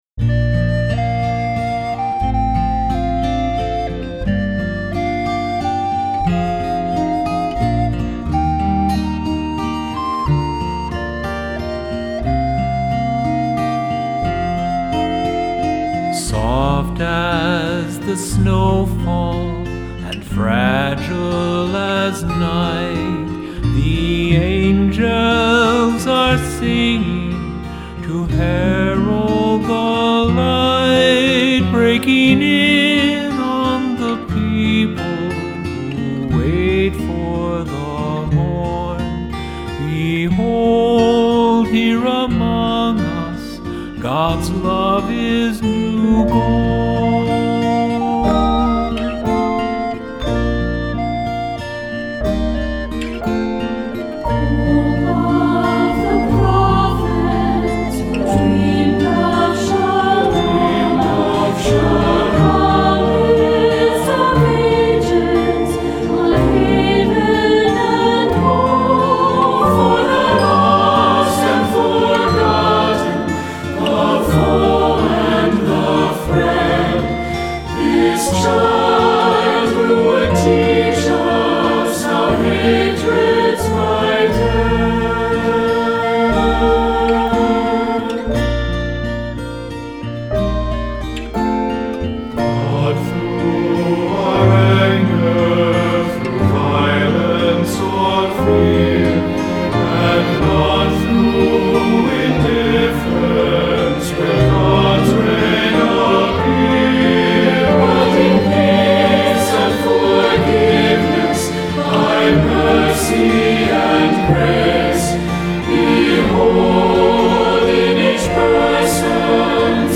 Accompaniment:      Keyboard
Music Category:      Choral